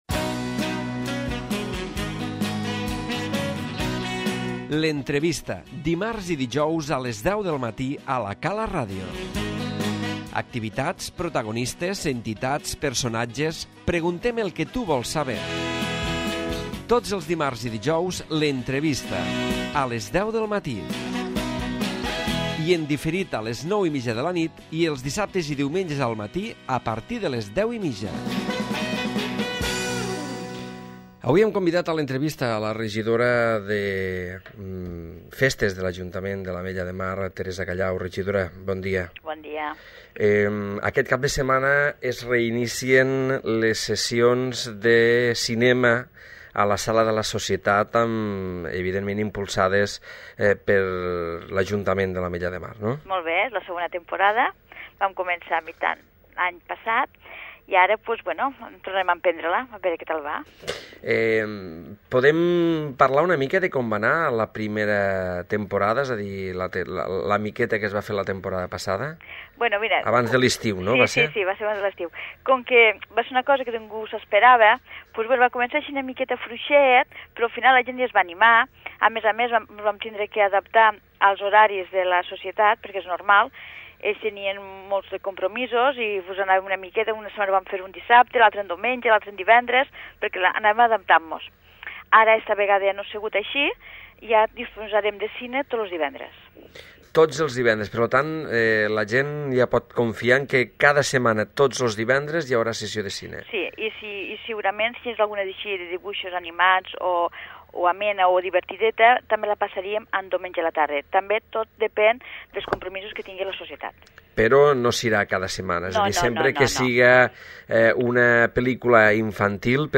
A l'entrevista d'avui hem parlat amb Teresa Callau, Regidora de Festes de l'Ajuntament de l'Ametlla de Mar, sobre l'inici de les sessions de cinema a la Sala de la SCER